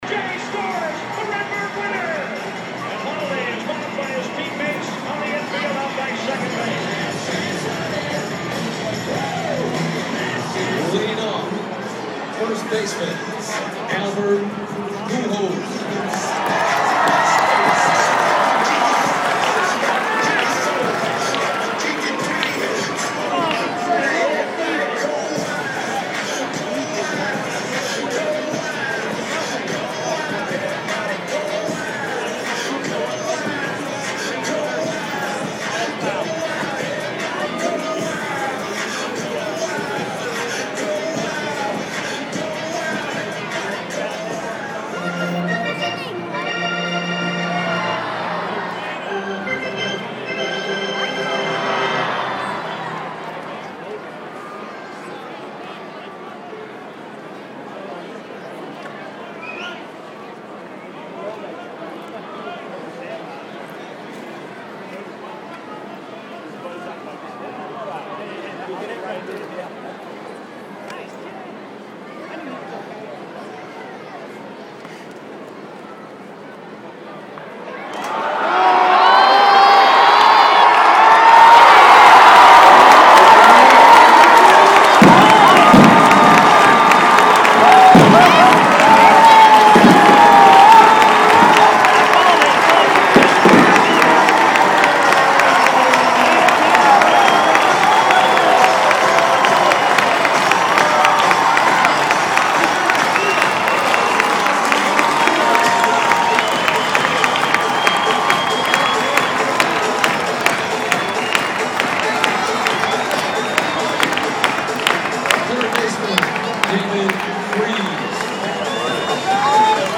When we were at the Cards/Padres game over Opening Weekend, I decided it would be a cool idea to record some of the ambient noise just using the memo feature on my iPhone.
I had completely forgotten about this until just this morning—and when I checked, I realized that I’d recorded a Pujols home run (his first of this year, actually) and the crowd reaction to it. If you listen very carefully, you can hear the ball jump off the bat.
(We were also in Left Field Terrace seats for the game, so we were pretty close to the noise.)